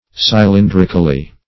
Search Result for " cylindrically" : The Collaborative International Dictionary of English v.0.48: cylindrically \cy*lin"dric*al*ly\ (s?-l?n"dr?-kal-l?), adv. In the manner or shape of a cylinder; so as to be cylindrical.
cylindrically.mp3